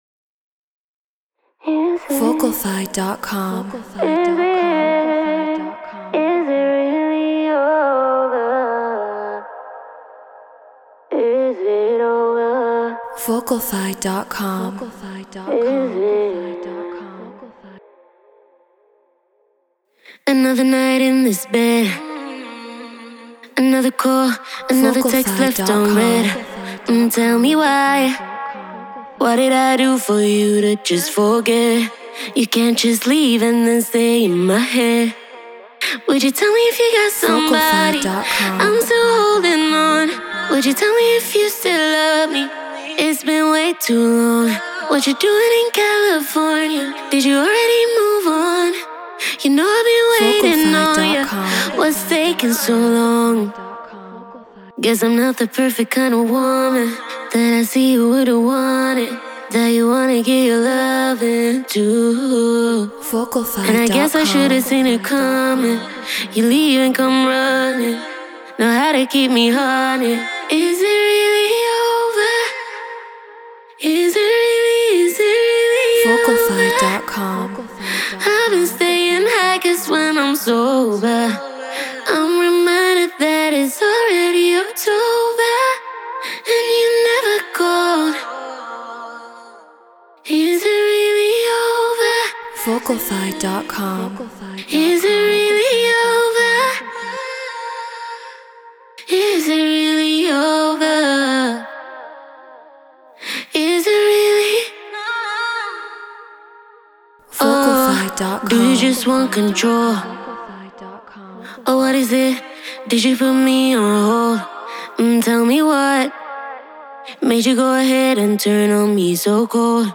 RnB 109 BPM A#min
Human-Made